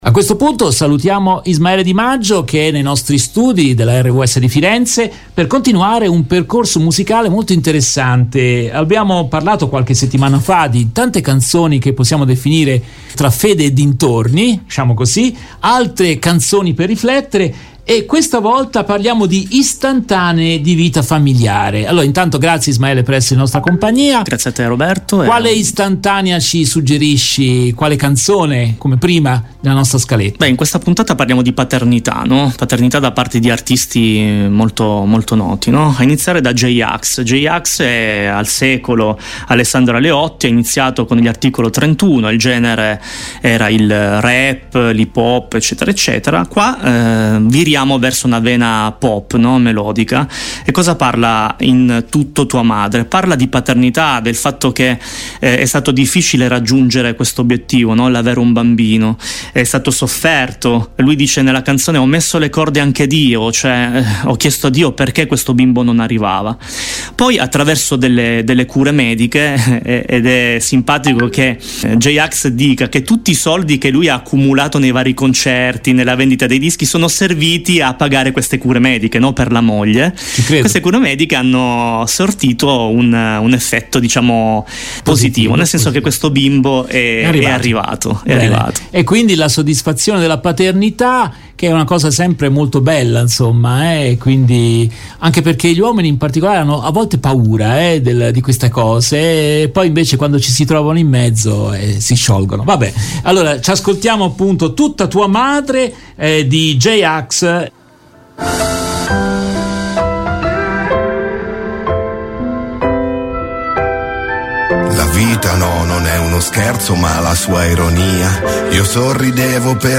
Serie di trasmissioni